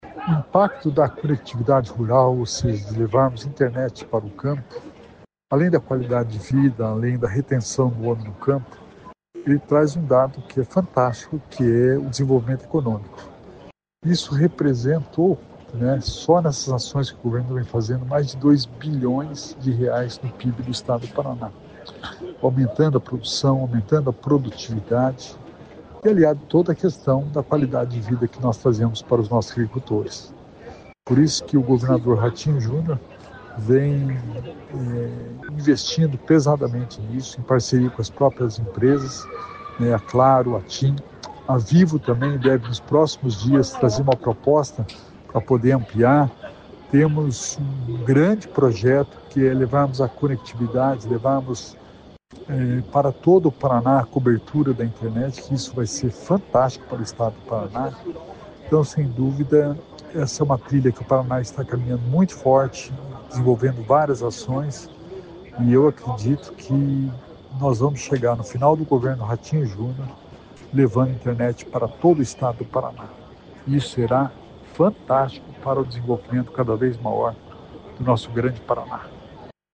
Sonora do secretário Estadual da Inovação e Inteligência Artificial, Alex Canziani, sobre os resultados da expansão da conectividade rural